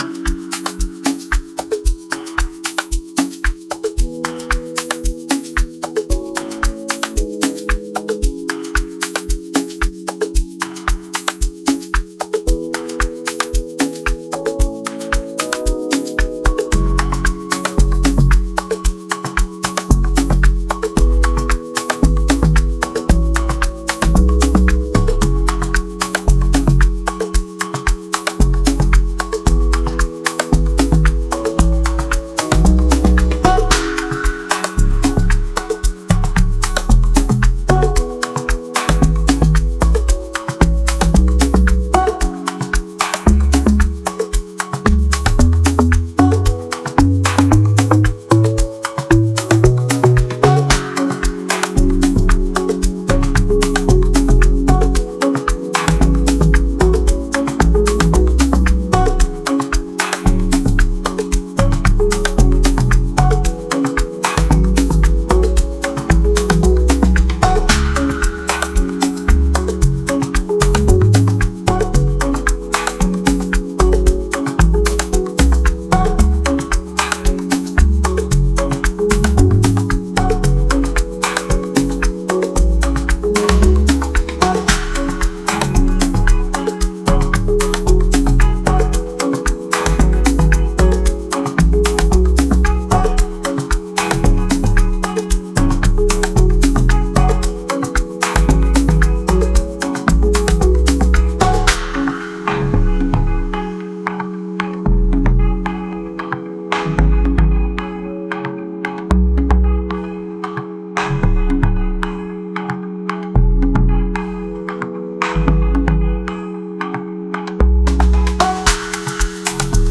Afrobeat
Groovy, Elegant
112 BPM